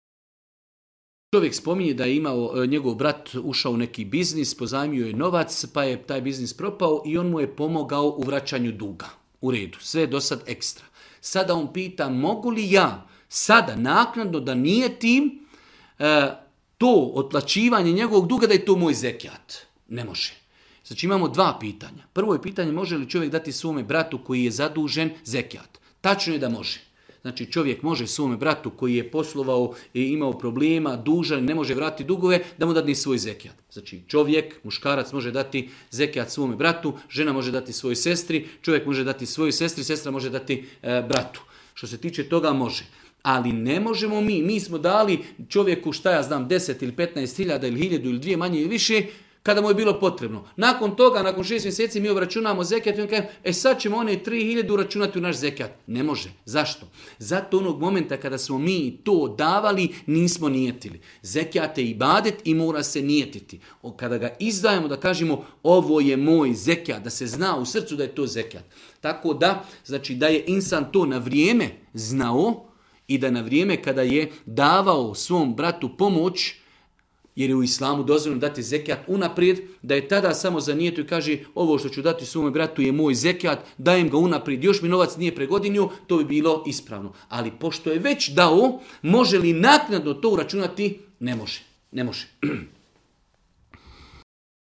video predavanju